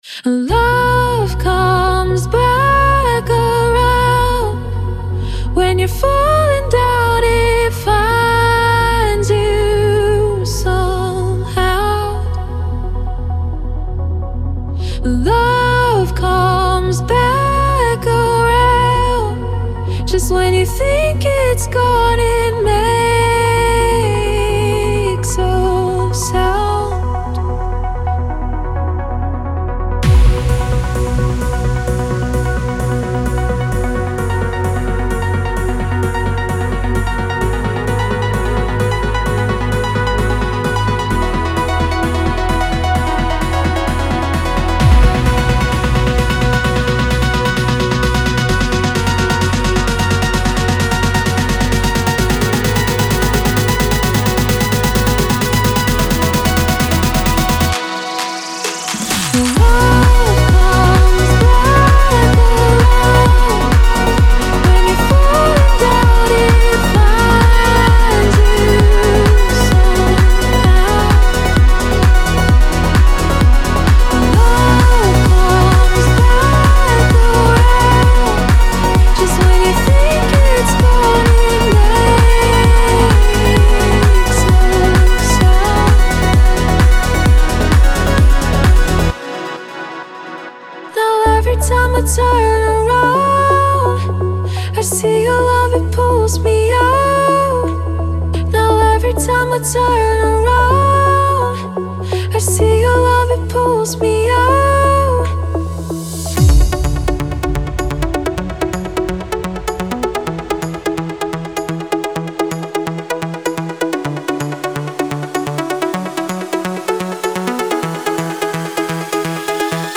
Progressive House Trance
• 69 Loop Files (Melodic parts, drums, etc.)
• 5 Vocal Stems
• 127–130 BPM